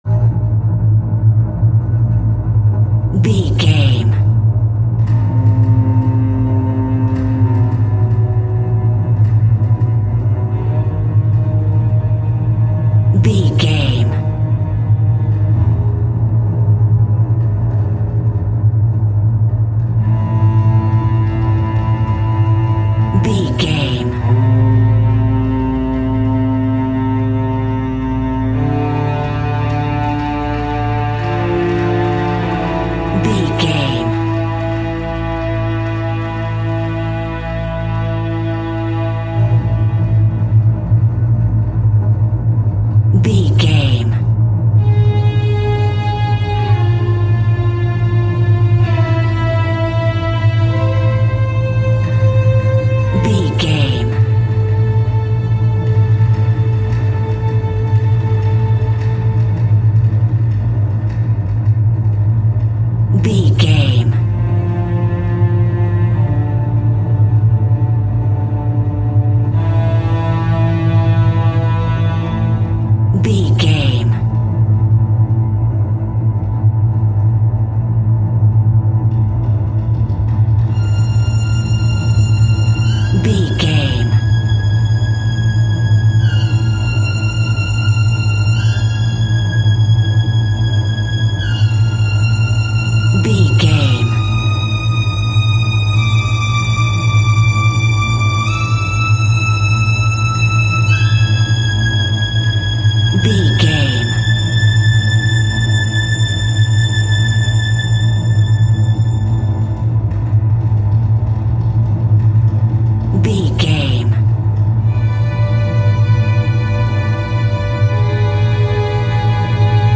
A Scary Film Atmosphere.
In-crescendo
Thriller
Aeolian/Minor
Slow
ominous
dark
eerie
strings
synthesiser
cello
drums